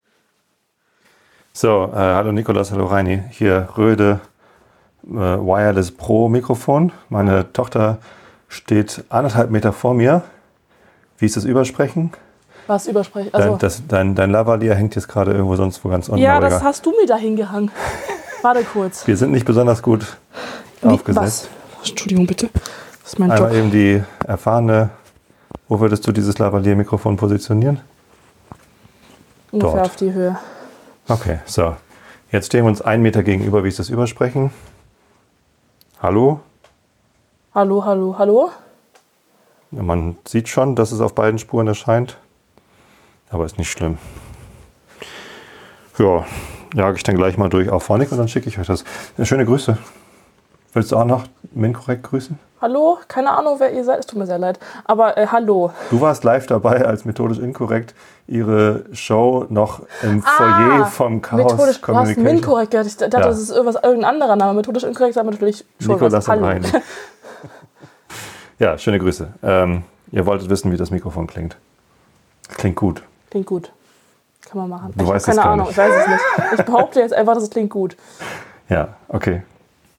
Ich hatte die DJI mics ebenfalls im Blick, hab mich dann aber für die Røde Wireless Pro entschieden, weil ich irgendwie geglaubt habe, der Sound ist bestimmt besser.
Wir haben Euch mal eben einen Test aufgenommen, zwei Spuren in Reaper / Ultraschall, Auphonic multi track, alle Settings auf default.
Ich mag die kleinen Røde Stecker, aber auch die Lavaliere mit Puschel, fühlt sich alles sehr wertig an.